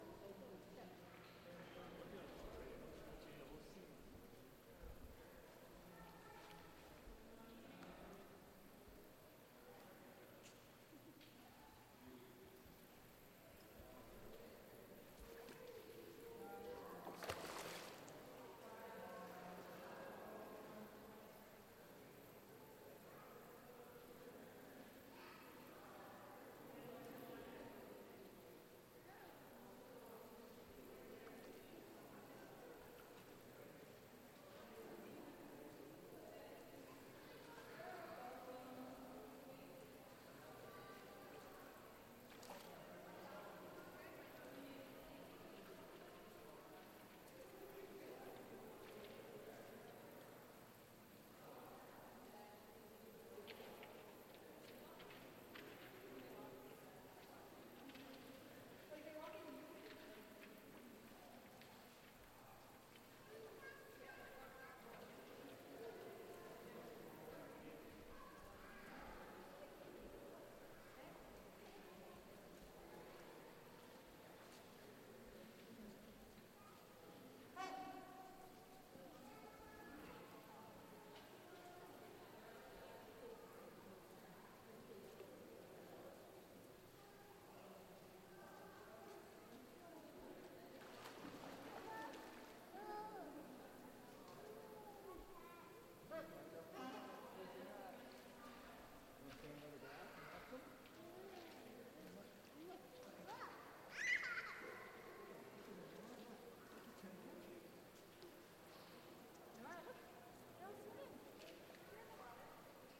标签： 动物的声音 背景声 鸟鸣 音景 哺乳动物 氛围 环境 动物 动物园 氛围 现场录音 噪音 大气
声道立体声